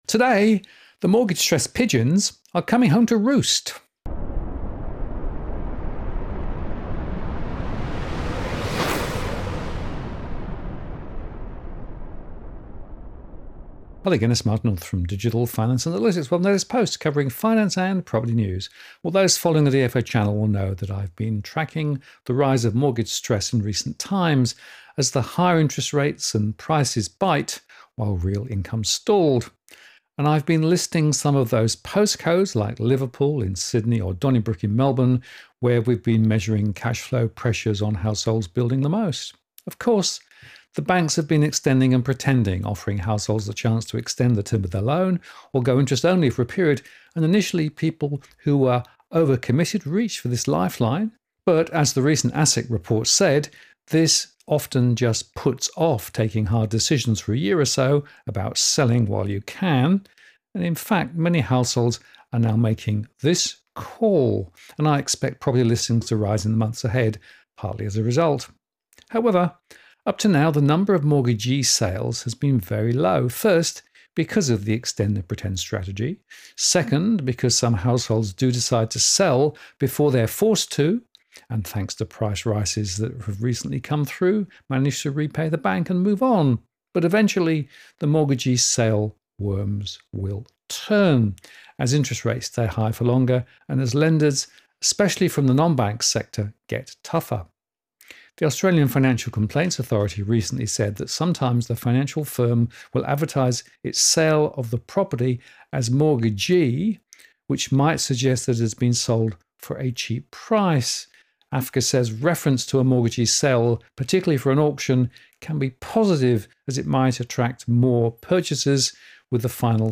DFA Live Replay Q&A: A Deep Dive On Post Codes Feeling The Pinch